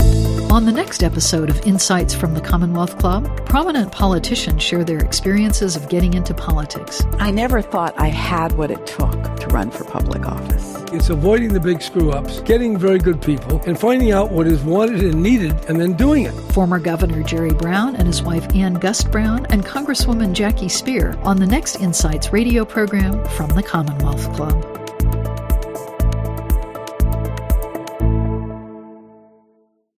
Ep002 Promo :30